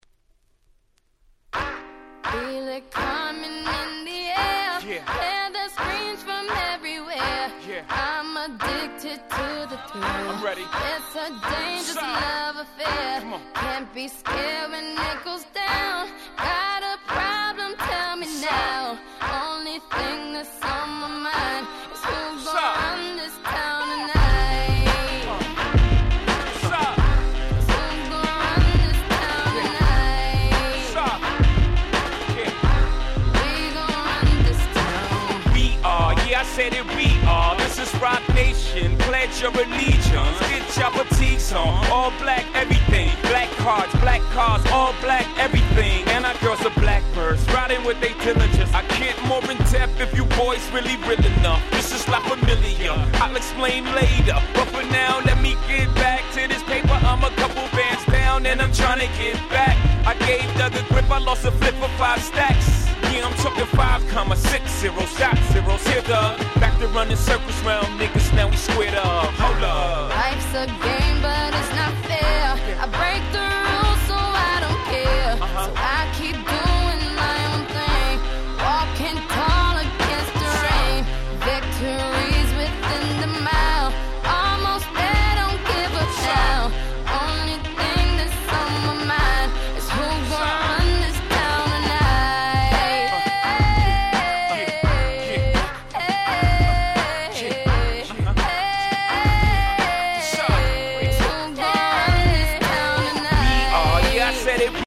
09' Super Hit Hip Hop !!